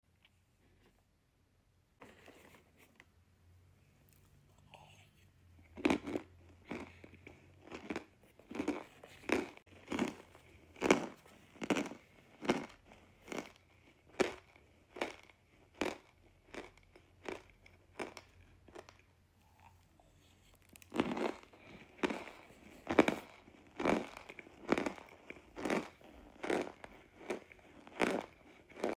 Love you all ❤ *ASMR PURPOSE ONLY* Can’t Film Right Now, But Sound Effects Free Download.